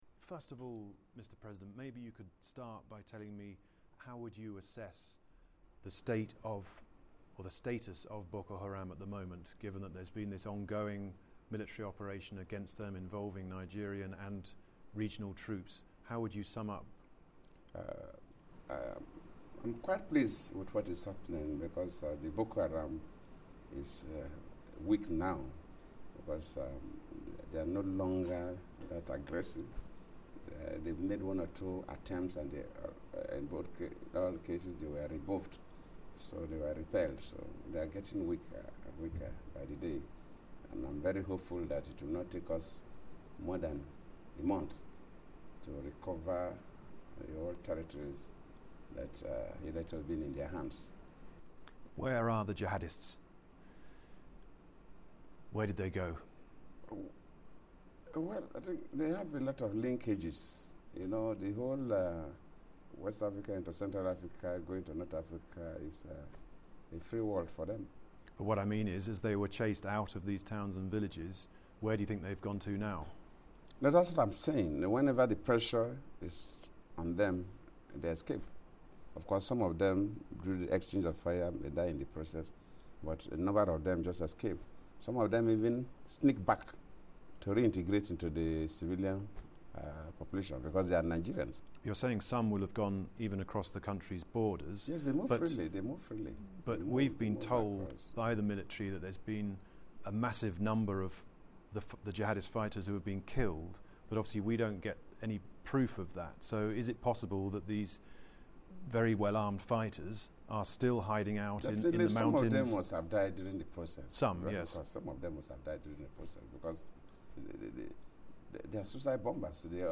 My BBC interview with President Goodluck Jonathan on Boko Haram